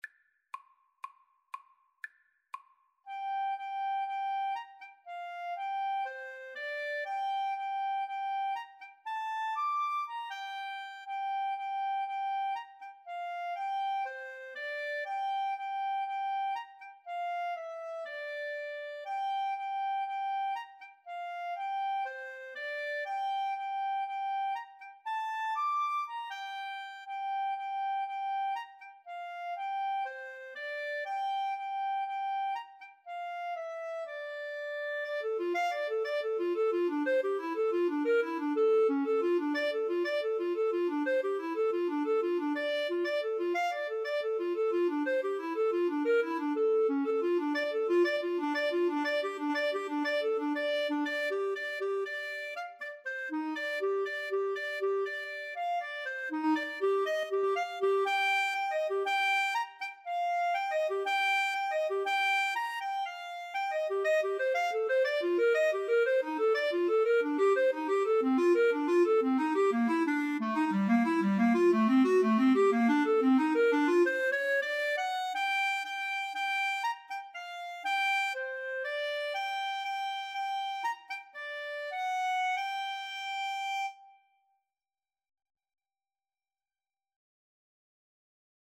Free Sheet music for Clarinet-Viola Duet
G minor (Sounding Pitch) (View more G minor Music for Clarinet-Viola Duet )
Allegro (View more music marked Allegro)
Classical (View more Classical Clarinet-Viola Duet Music)